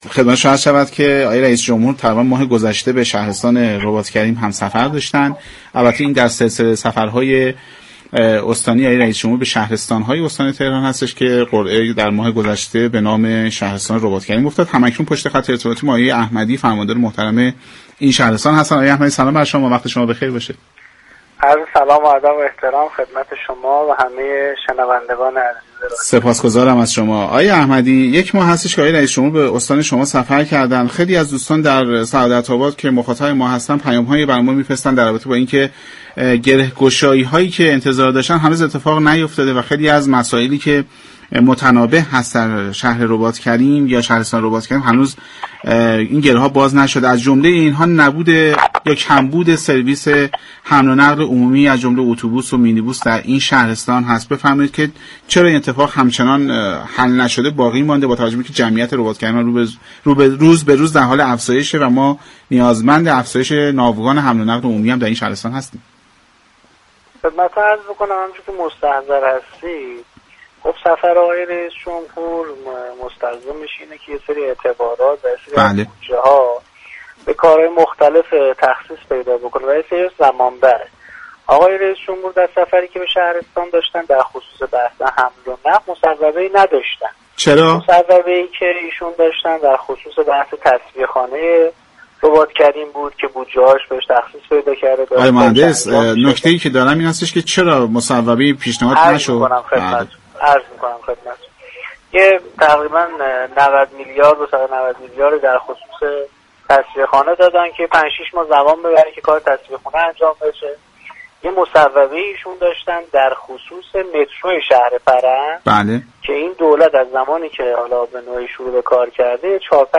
امید احمدی فرماندار شهرستان رباط‌كریم در گفت و گو با برنامه سعادت آباد 26 بهمن